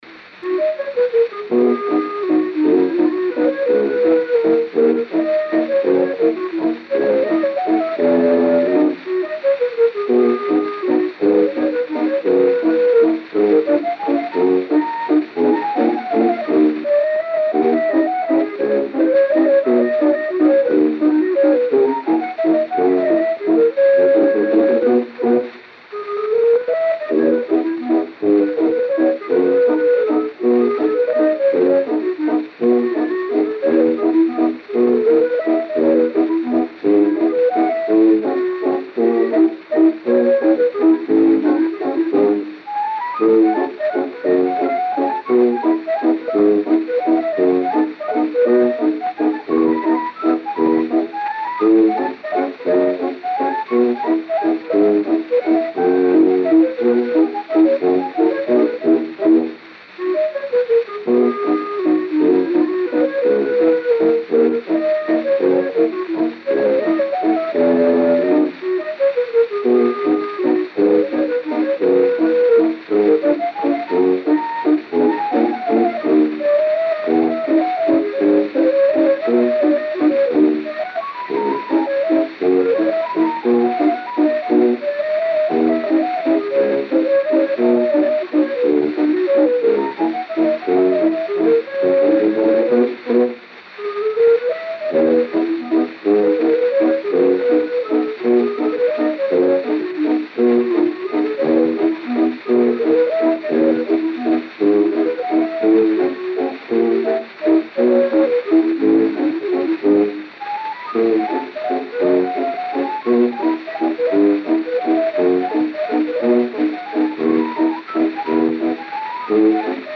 Disco de 78 rotações, também chamado "78 rpm", gravado em apenas um lado e com rótulo "rosa".
O responsável pela performance da música gravada não consta no rótulo, ao invés disso, está "Choro de clarinetto".